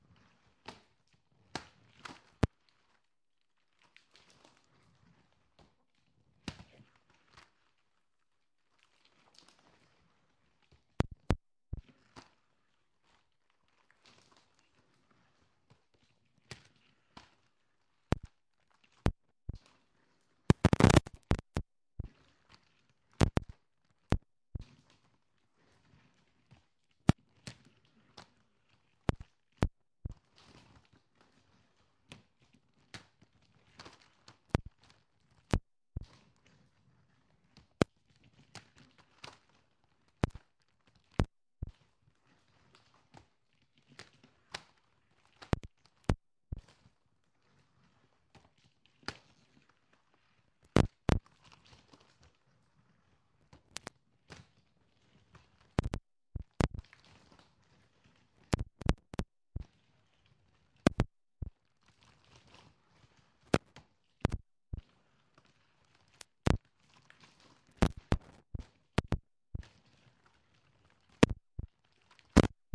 尖叫声嘎吱嘎吱 "的门声03
描述：Gate Squeaking, Variation 3 of 3. Unprocessed 44.1KHz, 16bit, Wav.
标签： 关闭 房子 金属 开放
声道立体声